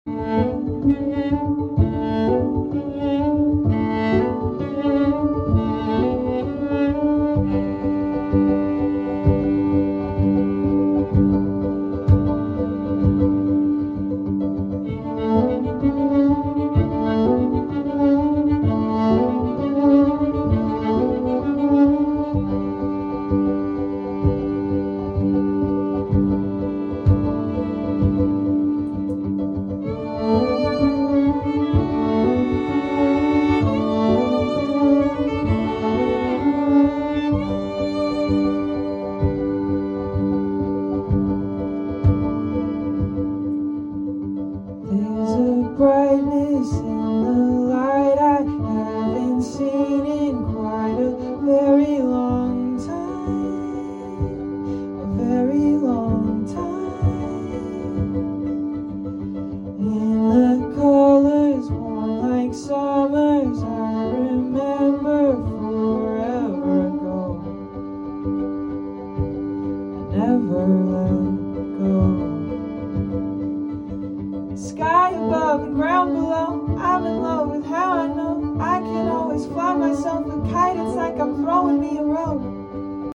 An ASMR version of the sound effects free download
An ASMR version of the calm mix.